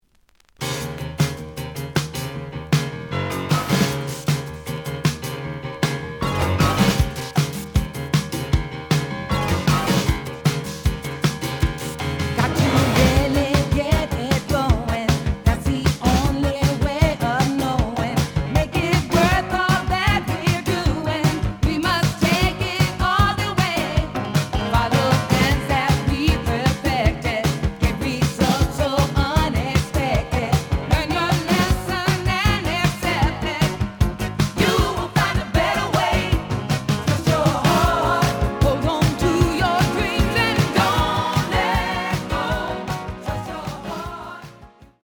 The audio sample is recorded from the actual item.
●Format: 7 inch
●Genre: Soul, 80's / 90's Soul
Slight edge warp. But doesn't affect playing.